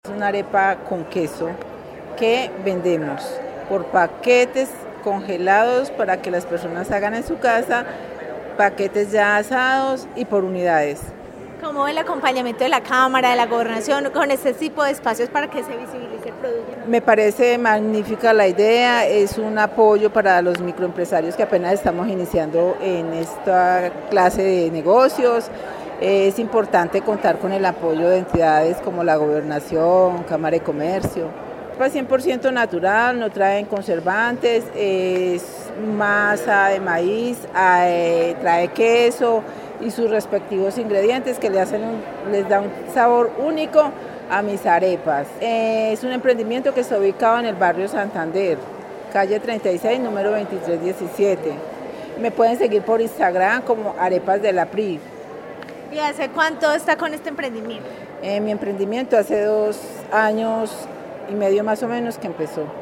Emprendedora